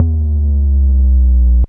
bass05.wav